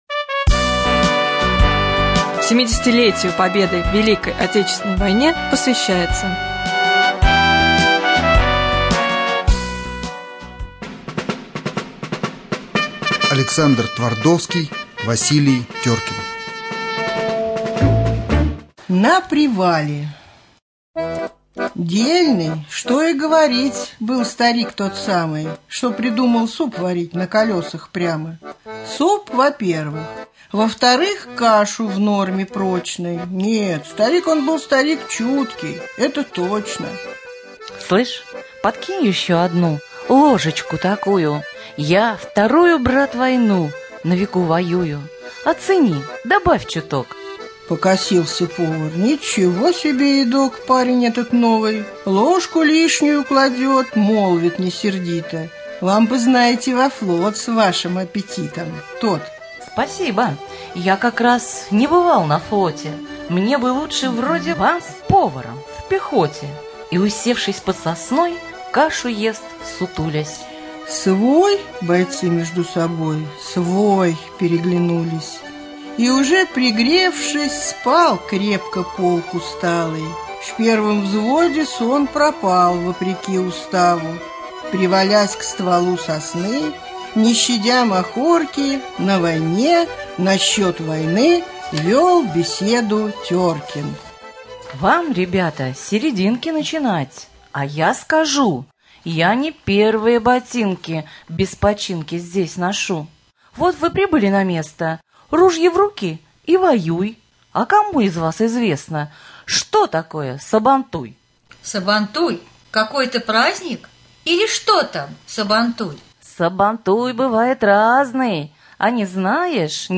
4.Поэма Александра Твардовского «Василий Теркин» 2 глава.